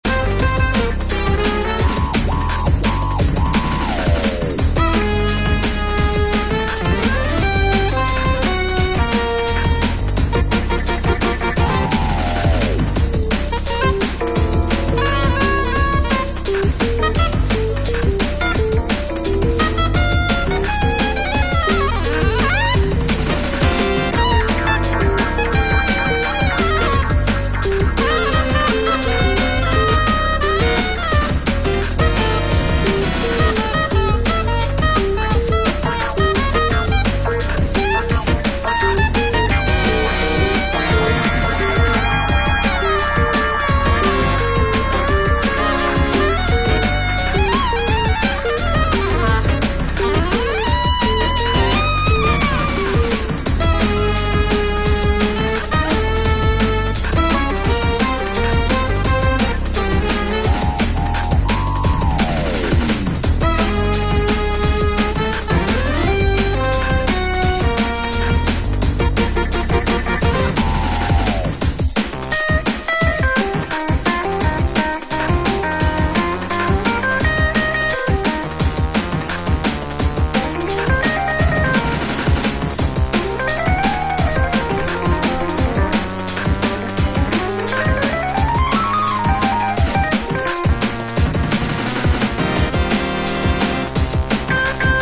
broken beat slanted afro piece
Techno